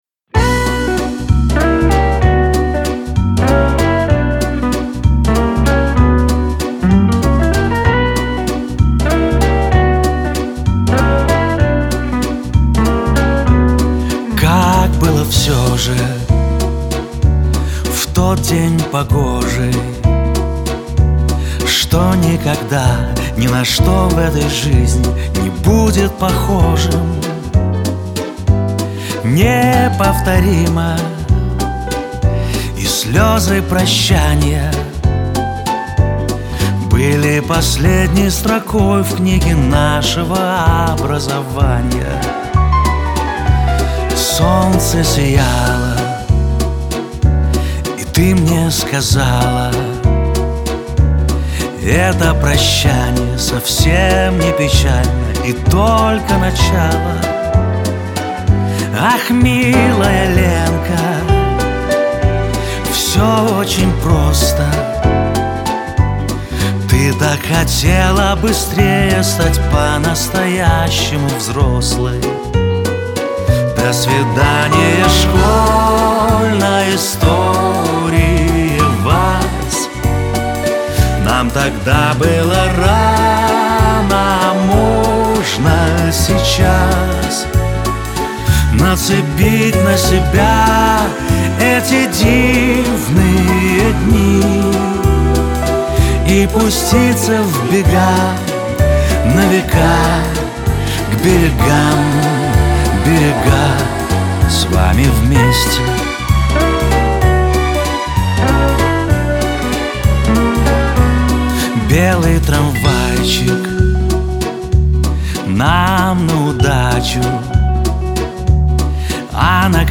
• Категория: Детские песни
школьный вальс